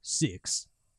Voices / Male / 6.wav